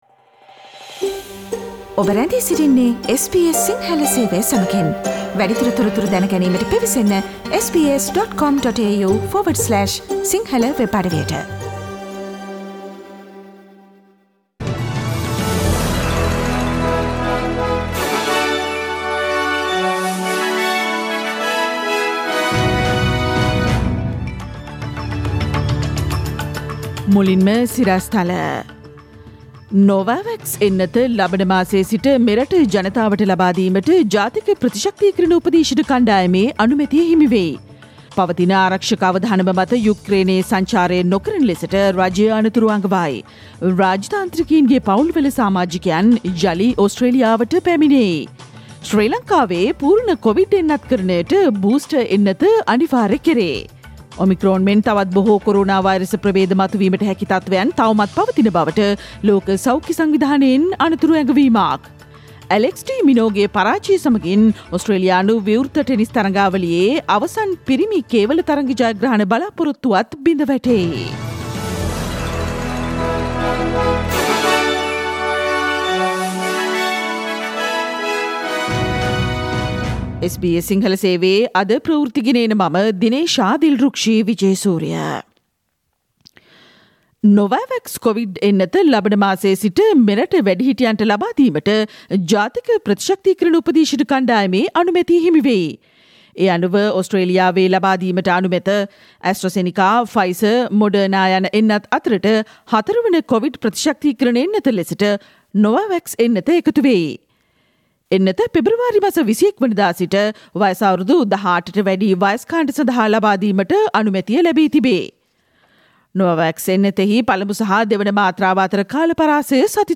ජනවාරි 25 වන අඟහරුවාදා SBS සිංහල ගුවන්විදුලි වැඩසටහනේ ප්‍රවෘත්ති ප්‍රකාශයට සවන්දෙන්න ඉහත චායාරූපය මත ඇති speaker සලකුණ මත click කරන්න